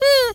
pgs/Assets/Audio/Animal_Impersonations/mouse_emote_02.wav
mouse_emote_02.wav